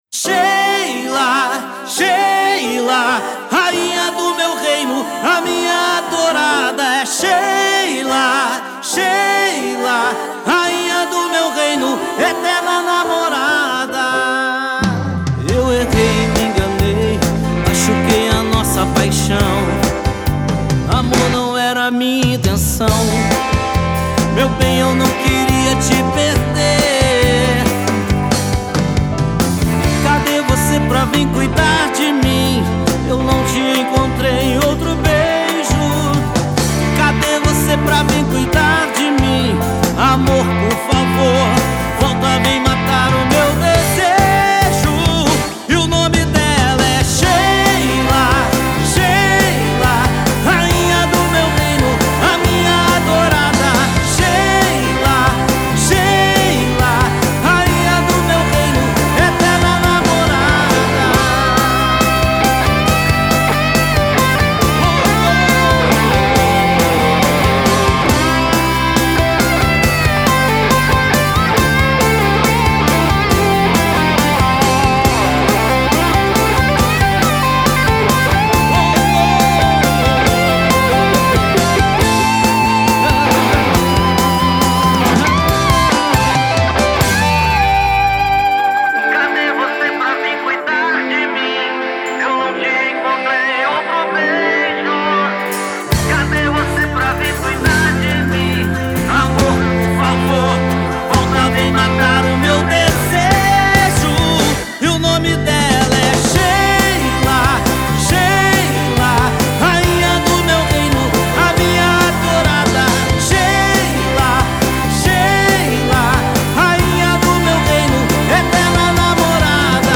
Brazilian Alternative Rock